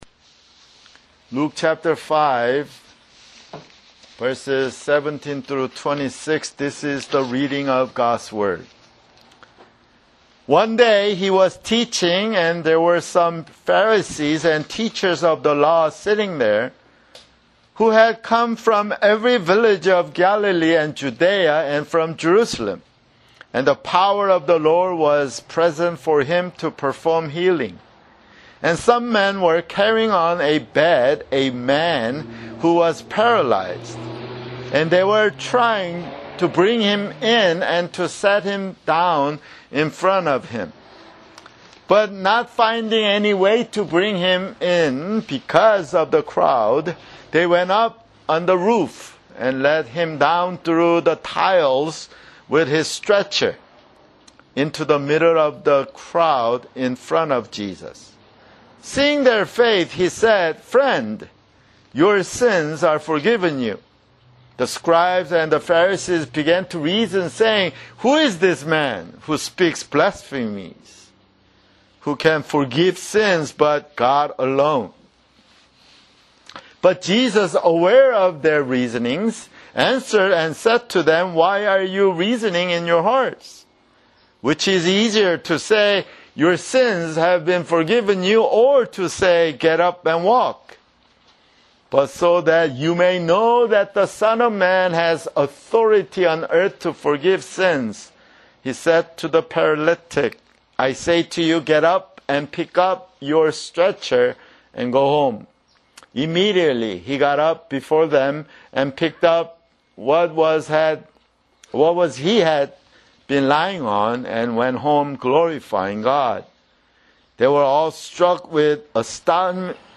[Sermon] Luke (34)